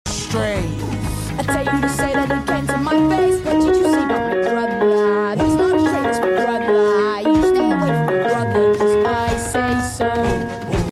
Piano Tutorial